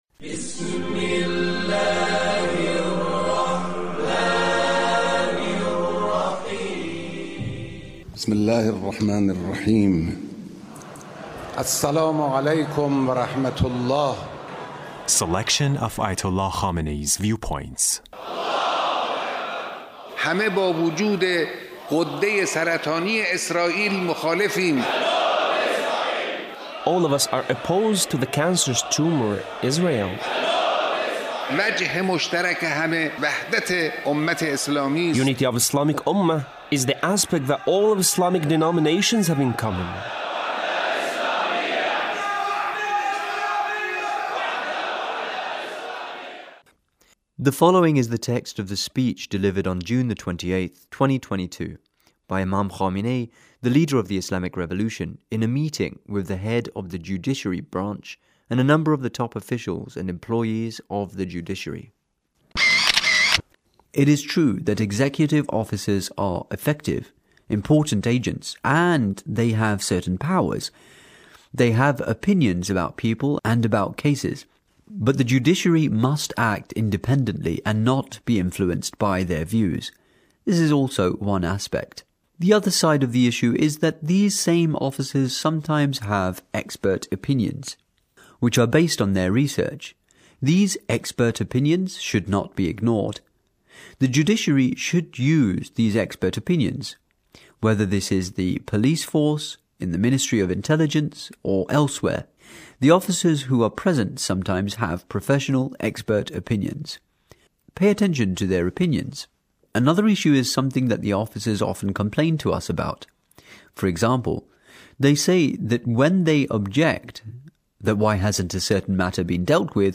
The Leader's speech in a meeting with a number of the top officials and employees of the Judiciary.